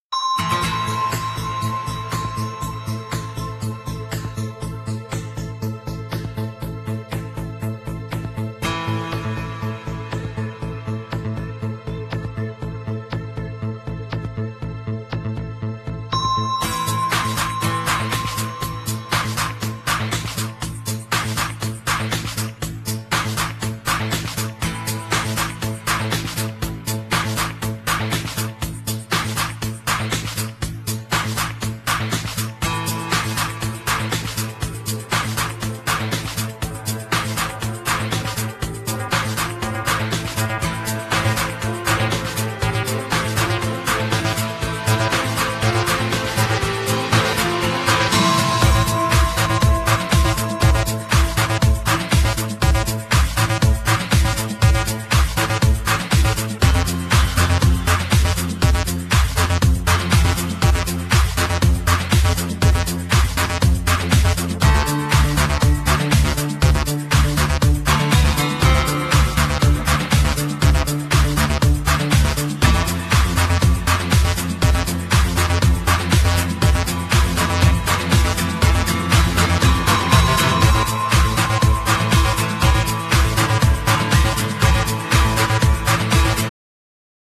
Genere : House